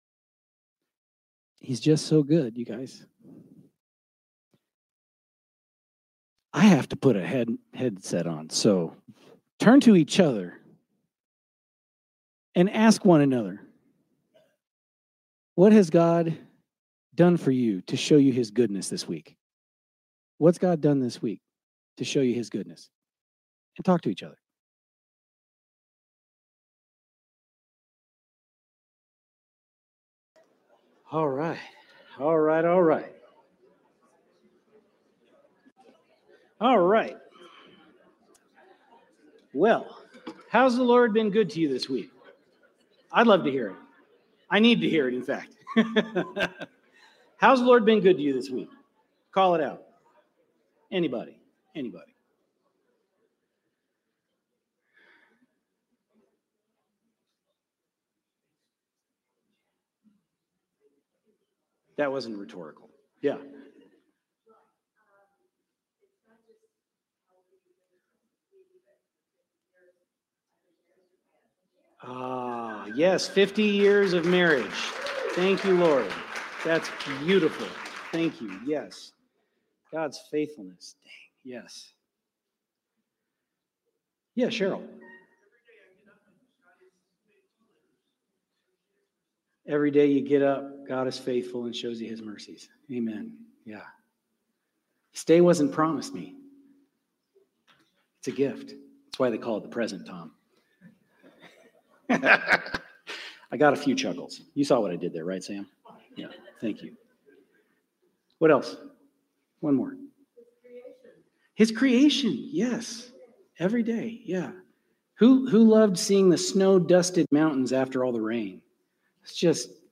Sermon from Celebration Community Church on September 28, 2025